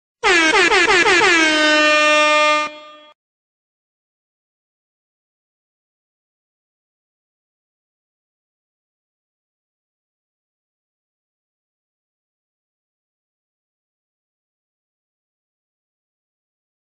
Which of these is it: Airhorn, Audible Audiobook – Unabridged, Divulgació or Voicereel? Airhorn